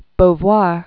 (bō-vwär), Simone de 1908-1986.